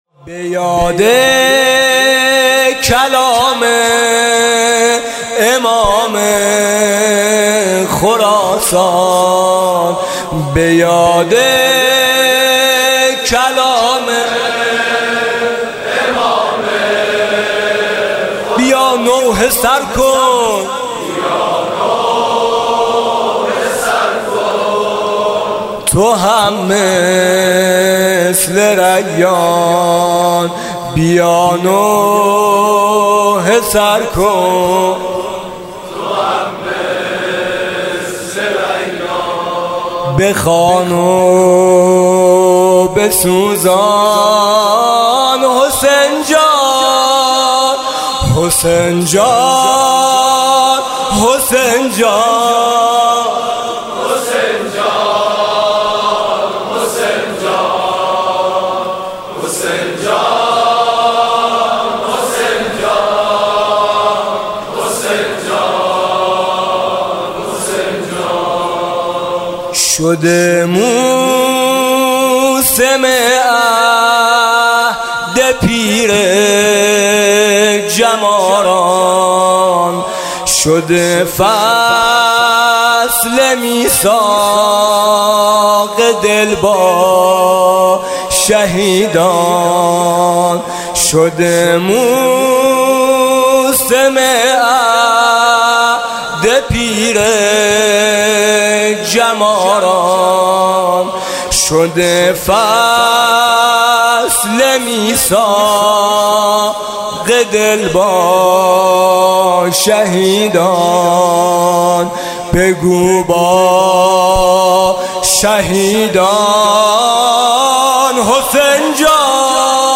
صوت مراسم شب اول محرم 1438 هیئت میثاق با شهدا ذیلاً می‌آید:
زمینه: مسلم توام، کوفه میا کوفه میا